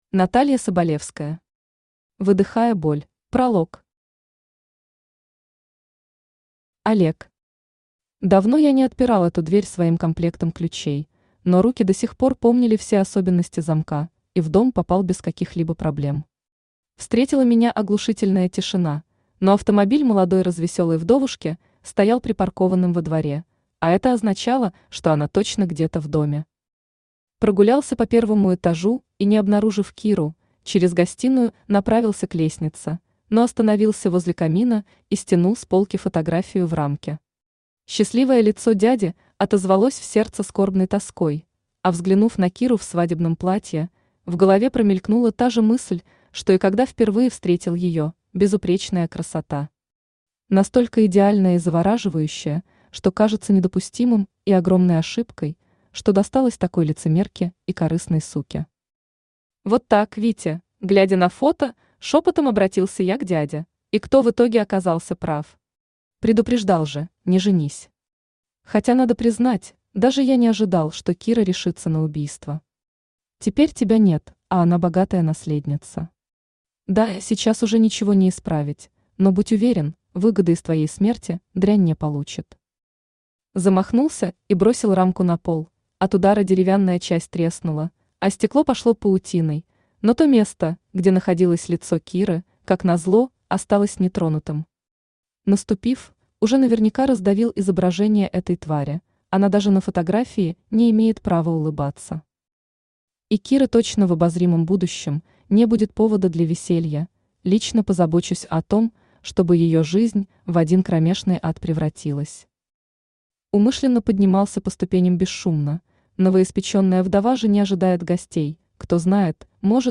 Аудиокнига Выдыхая боль | Библиотека аудиокниг
Aудиокнига Выдыхая боль Автор Наталья Соболевская Читает аудиокнигу Авточтец ЛитРес.